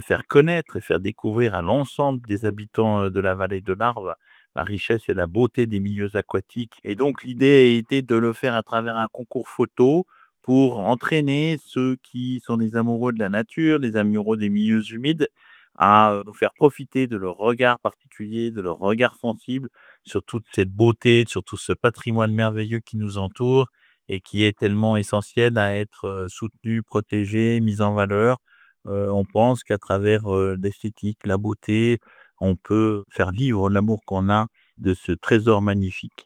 Bruno Forel est le président du syndicat et il explique justement le but de cette initiative.